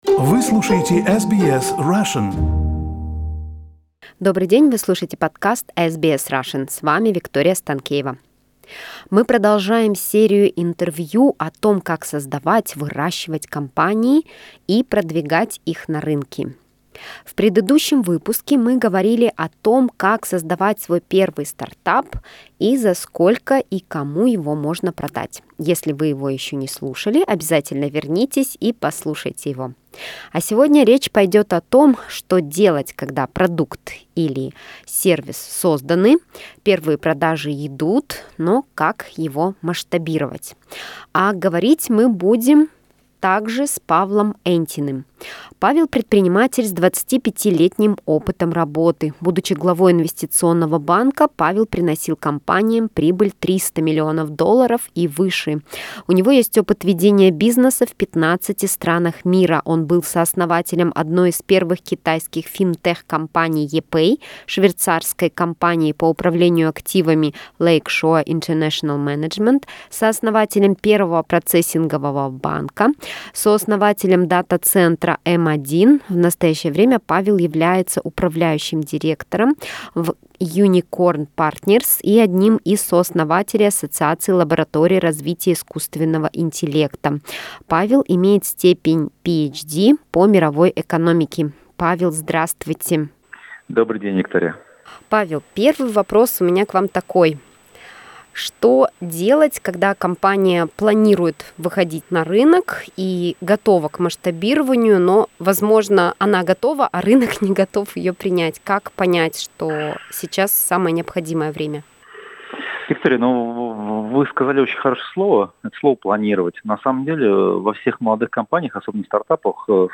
Второе интервью из цикла с советами для предпринимателей о том, как создавать, выращивать компании и продвигать их на рынки.